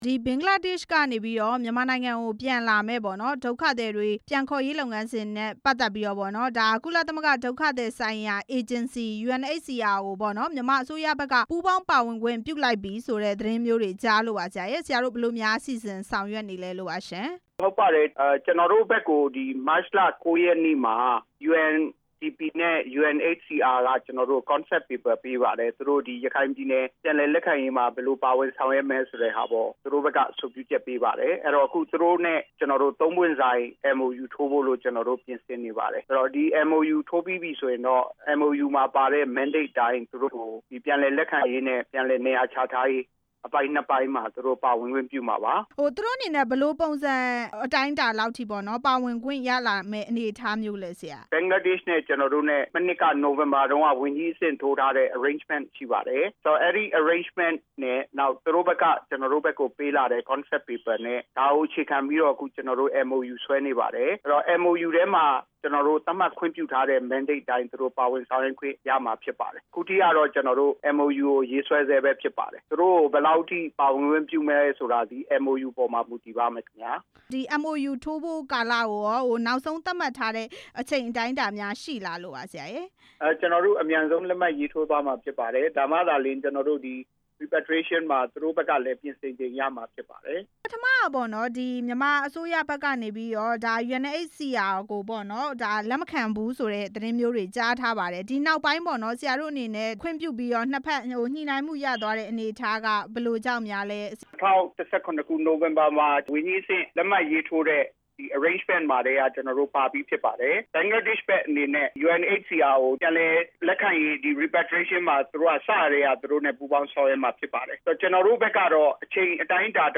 နိုင်ငံခြားရေးဝန်ကြီးဌာန အမြဲတမ်းအတွင်းဝန် ဦးမြင့်သူနဲ့ ဆက်သွယ်မေးမြန်းချက်